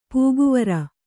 ♪ pūguvra